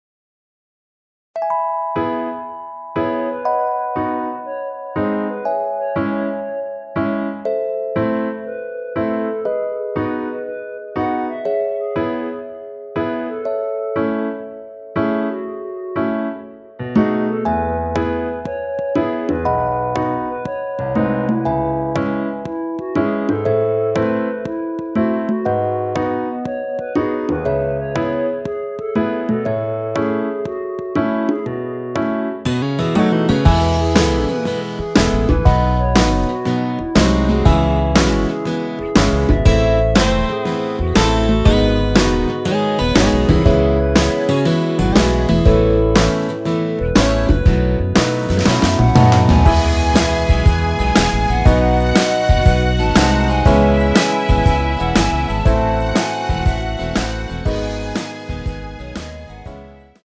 엔딩이 페이드 아웃이라 라이브 하시기 좋게 엔딩을 만들어 놓았습니다.
원키에서(-2)내린 멜로디 포함된 MR입니다.
앞부분30초, 뒷부분30초씩 편집해서 올려 드리고 있습니다.
중간에 음이 끈어지고 다시 나오는 이유는